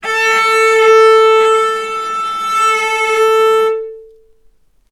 Strings / cello / sul-ponticello
vc_sp-A4-ff.AIF